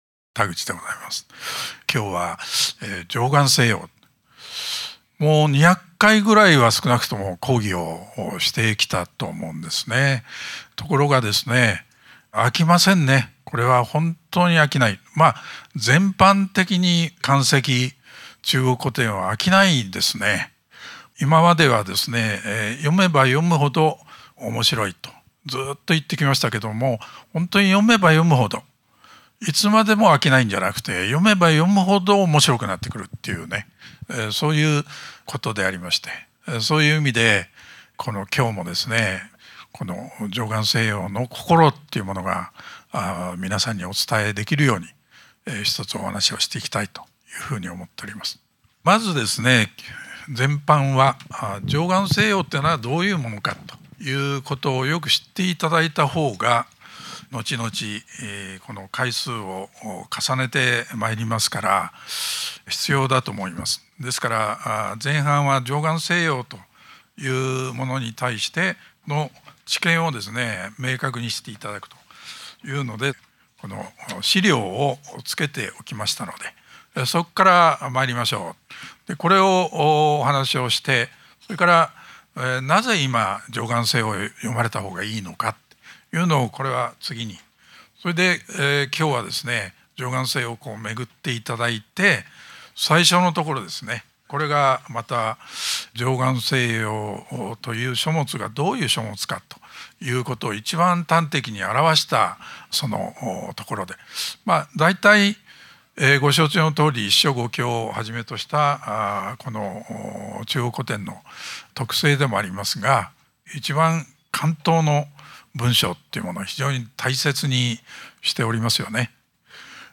【収録内容】 第1講 自己人生と自社のより良いリーダーとなる為に 第2講 いかに心を統御するか 第3講 良い部下と悪い部下を明確に知る 第4講 平穏無事が長く続く要点 第5講 何故もの事は中途半端で終わるのか 【収録時間】 11時間 ※この音声は2019年9月から2020年1月に開催された 致知出版社主催「人生と経営の極意を『貞観政要』に学ぶ」での 講演（全5講）を収録したものです。